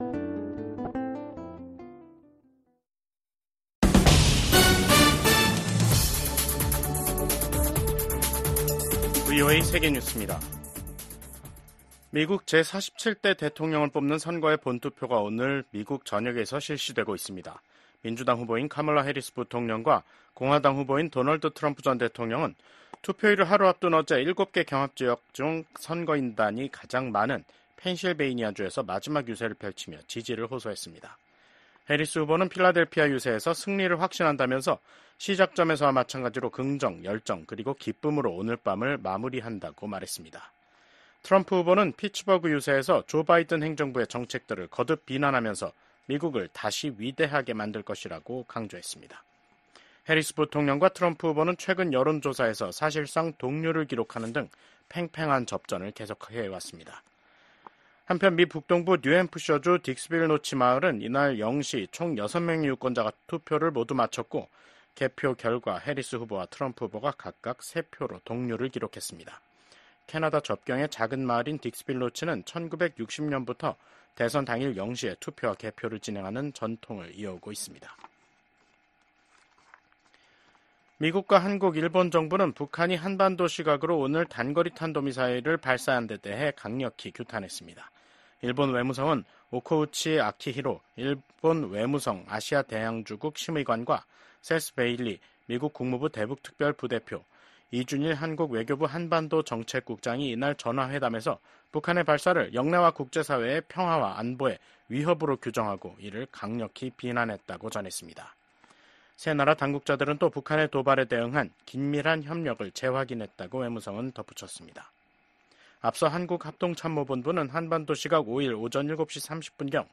VOA 한국어 간판 뉴스 프로그램 '뉴스 투데이', 미국 대통령 선거일을 맞아 VOA가 보내드리는 뉴스 투데이 미국 대선 특집방송 3부 시작하겠습니다.